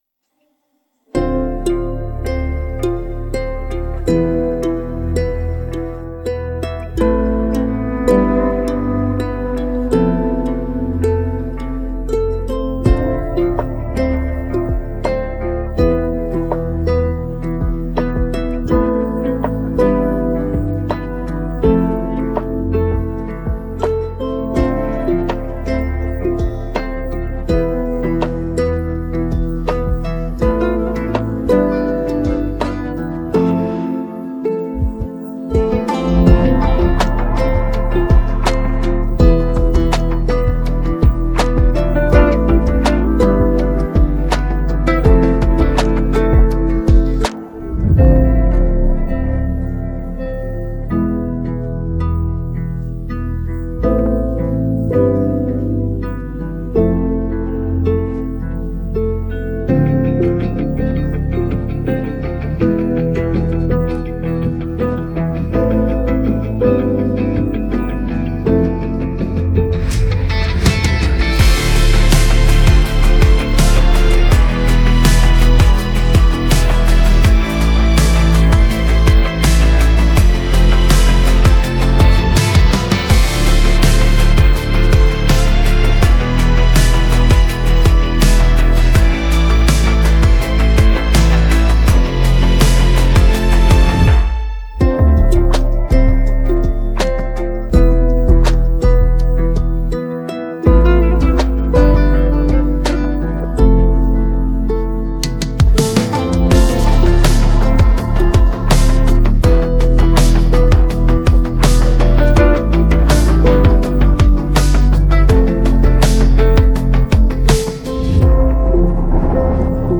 Качественный минус без бэк-вокала